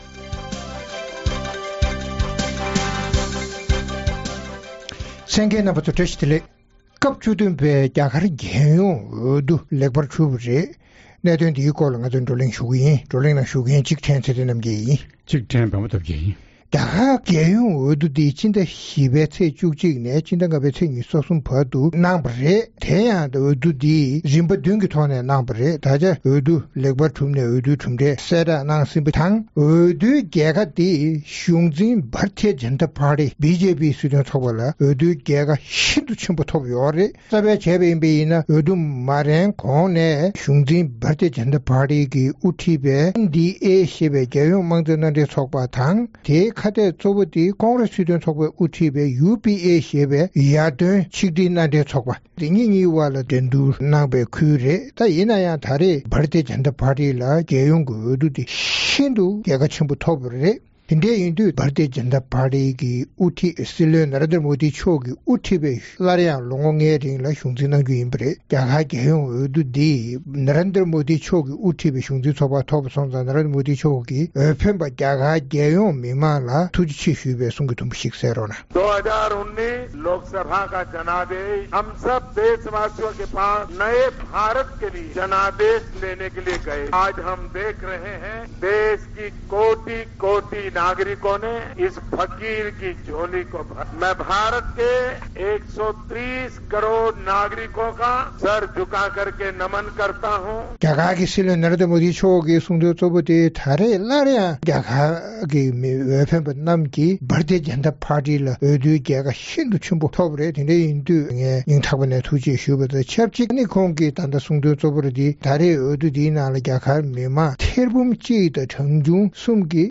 རྒྱ་གར་གྱི་BJP སྲིད་དོན་ཚོགས་པའི་དབུ་ཁྲིད་Narendra Modi མཆོག་གིས་སྲིད་བློན་ལས་གནས་ཀྱི་དམ་འབུལ་གནང་བ་དང་ཆབས་ཅིག་བློན་ཆེན་རྣམས་བསྐོ་གཞག་དམ་འབུལ་གནང་བ་སོགས་ཀྱི་སྐོར་རྩོམ་སྒྲིག་འགན་འཛིན་རྣམ་པས་བགྲོ་གླེང་གནང་བ་གསན་རོགས་གནང་།